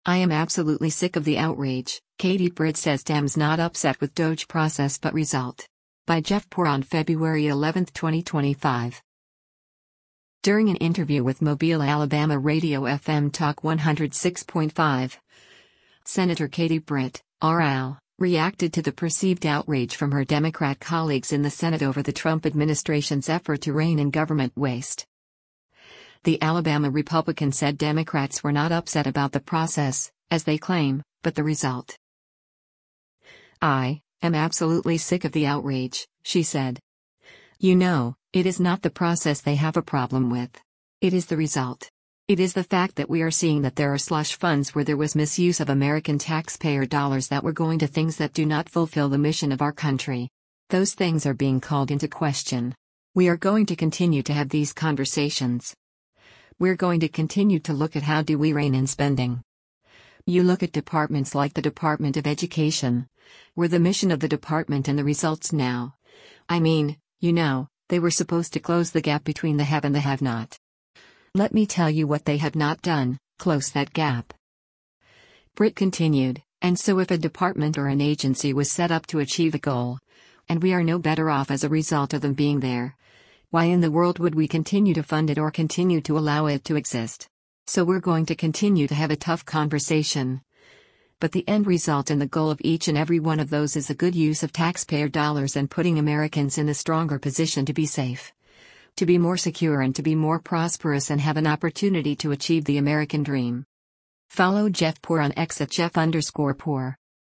During an interview with Mobile, AL radio FM Talk 106.5, Sen. Katie Britt (R-AL) reacted to the perceived “outrage” from her Democrat colleagues in the Senate over the Trump administration’s effort to rein in government waste.